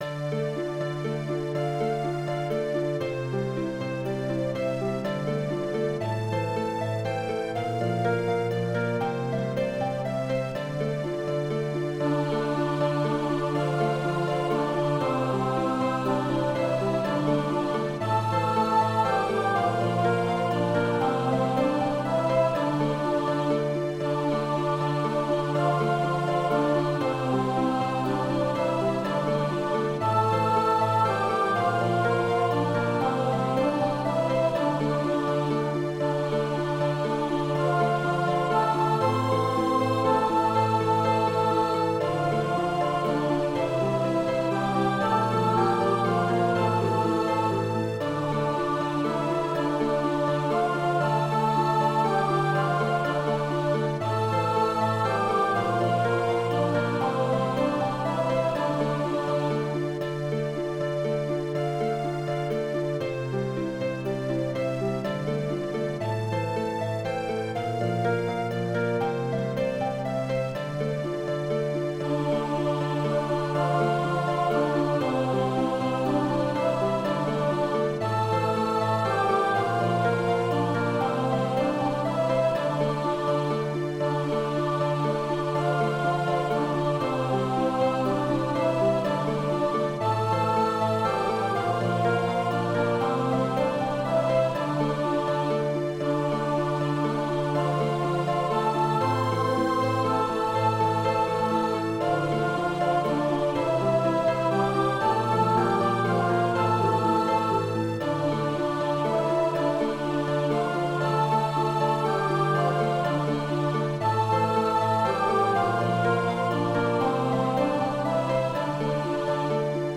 Choir Unison, Organ/Organ Accompaniment
Voicing/Instrumentation: Choir Unison , Organ/Organ Accompaniment
His music blends early music, 20th-century elements, and fundamentalist musical traditions